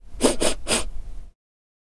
descargar sonido mp3 aspirar 3
sniff-short.mp3